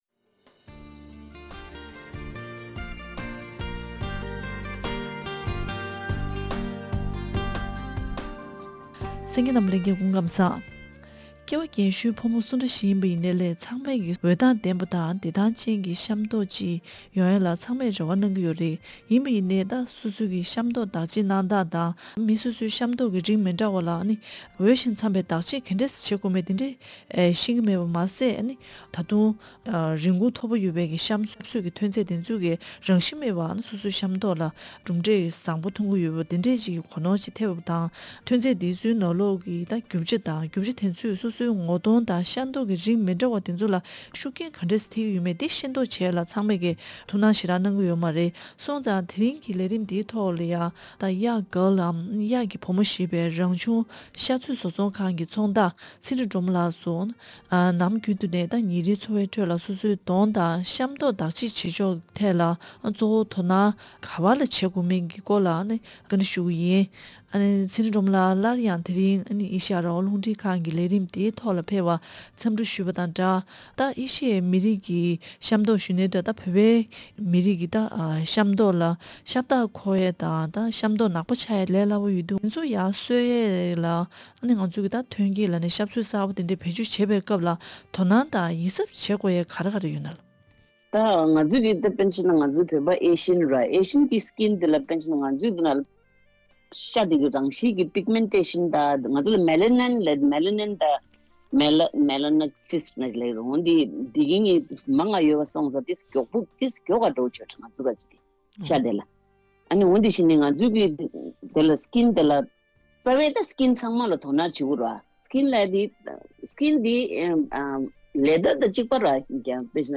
དེ་རིང་གི་བཅར་འདྲིའི་ལེ་ཚན་ནང་།